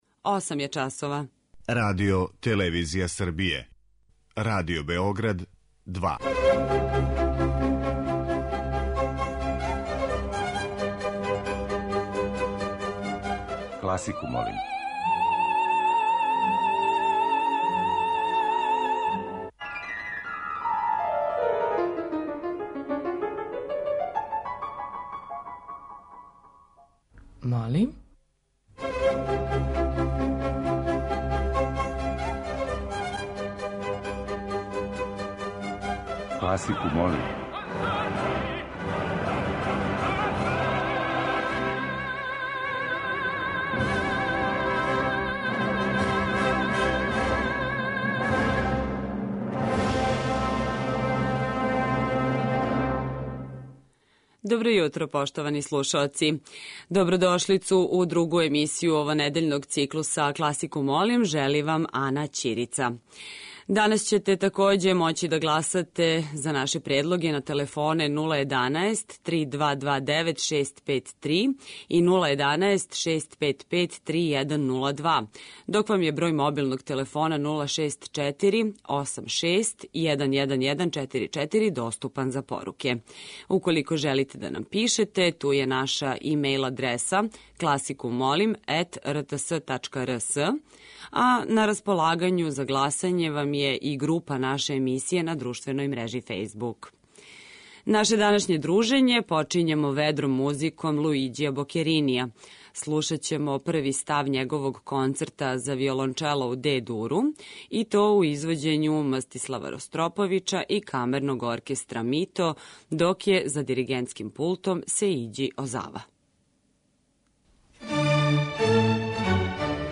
Чаробница је највише било у операма, па ће овонедељни циклус бити обједињен одломцима из оперских прича, а из пера Чајковског, Вагнера, Шарпантјеа и Хајдна.
Уживо вођена емисија, окренута широком кругу љубитеља музике, разноврсног је садржаја, који се огледа у подједнакој заступљености свих музичких стилова, епоха и жанрова.